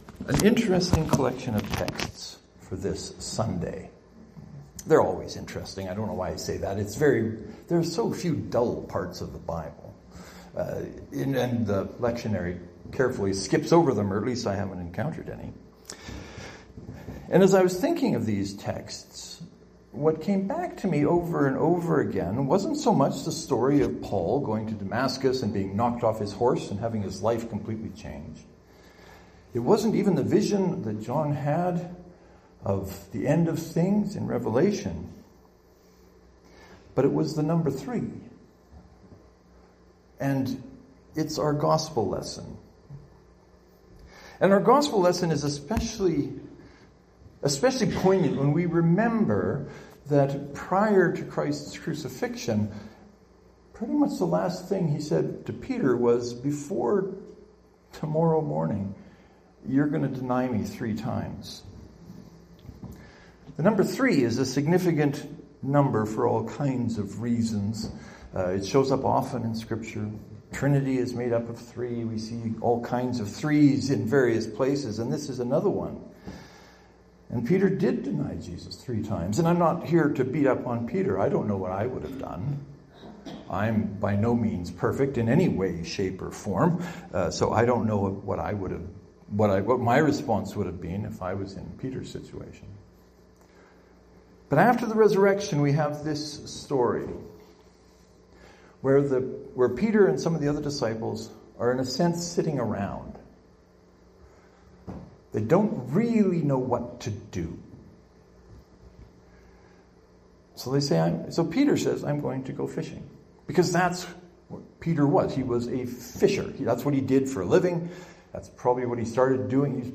My apologies for some of the sound at the beginning of the recording. It is due to some unfortunate movements on the pulpit . . . by me.